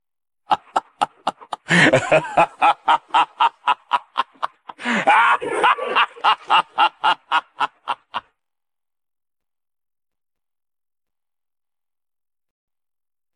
Efek suara Ketawa jahat
Kategori: Suara ketawa
Keterangan: Efek suara tawa jahat, cocok untuk memberikan sentuhan dramatis pada video.
efek-suara-ketawa-jahat-id-www_tiengdong_com.mp3